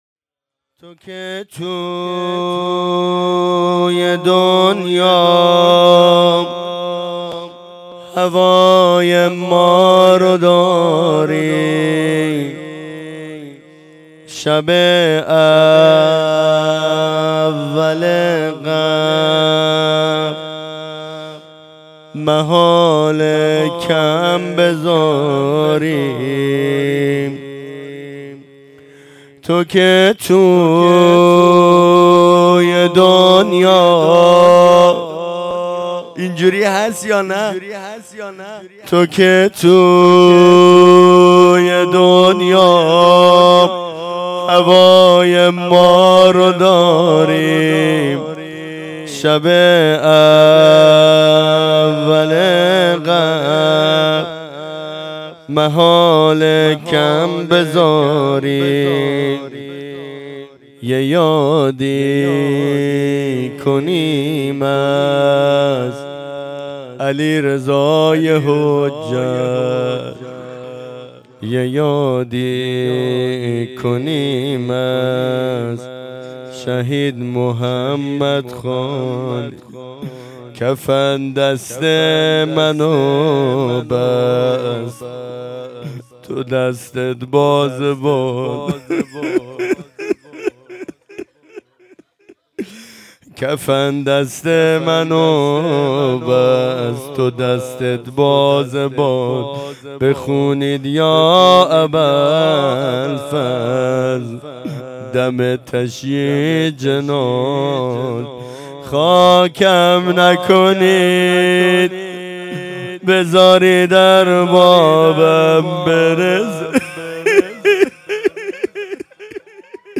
زمزمه